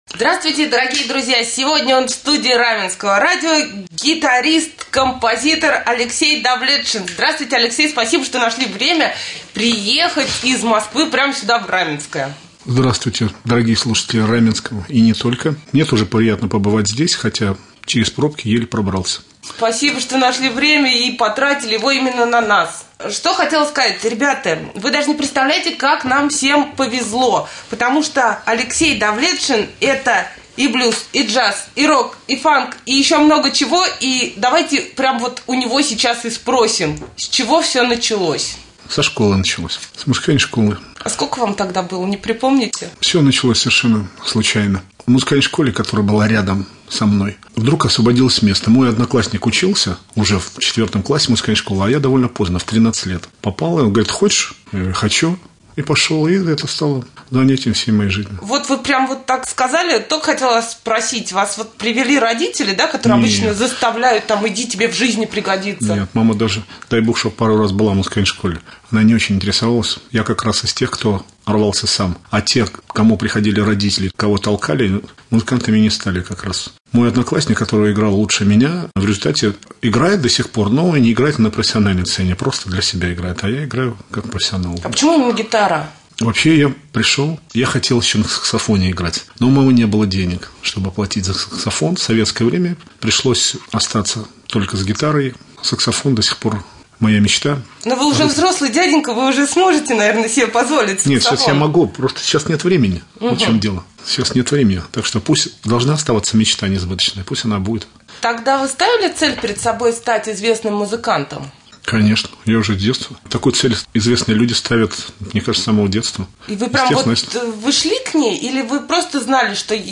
рассказал в студии Раменского радио о своем творческом пути